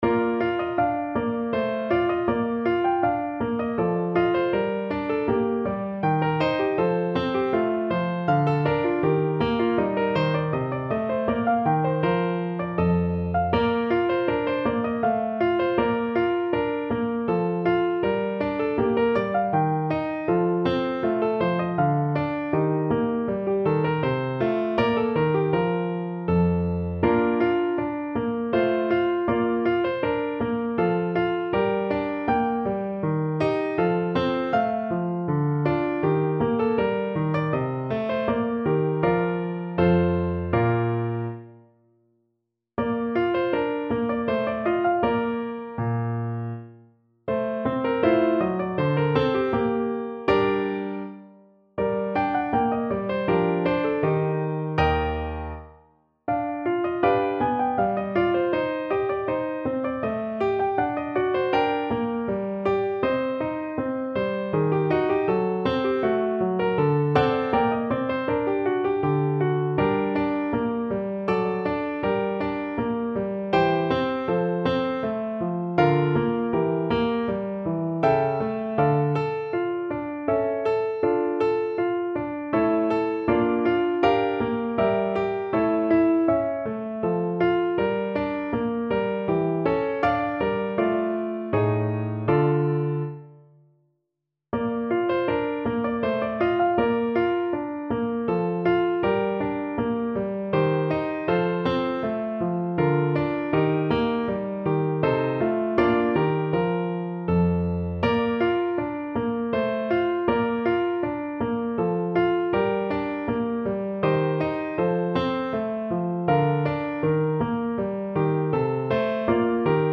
Play (or use space bar on your keyboard) Pause Music Playalong - Piano Accompaniment Playalong Band Accompaniment not yet available transpose reset tempo print settings full screen
Bb major (Sounding Pitch) (View more Bb major Music for Bassoon )
3/4 (View more 3/4 Music)
Andante
Classical (View more Classical Bassoon Music)